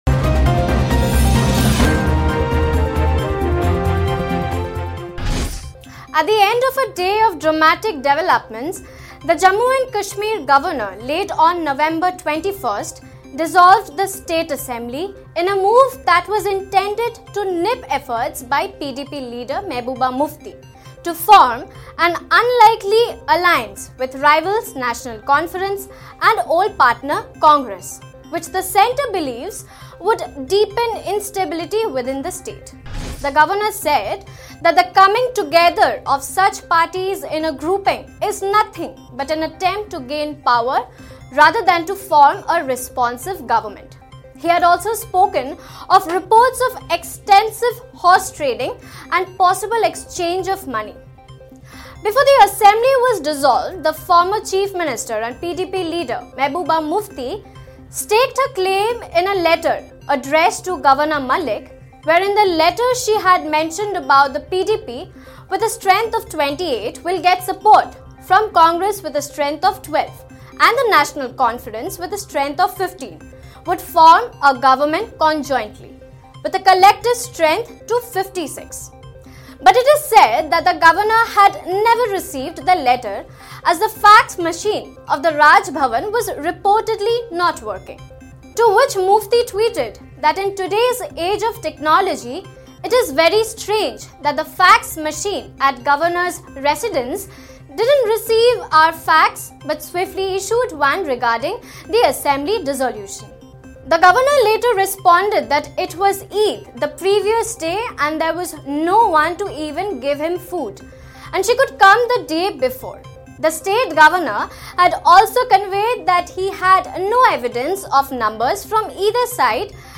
News Report / Is BJP losing the plot in Jammu & Kashmir?